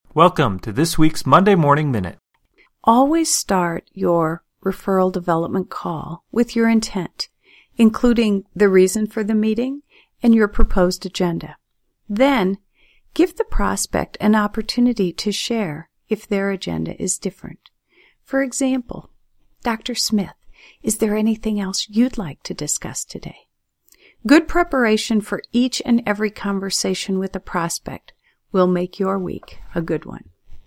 Welcome to B/Mc’s Monday Morning Minute, an audio message to jump start your week. The brief messages include reminders, new ideas and sometimes a little therapy to slide into the work week with ease.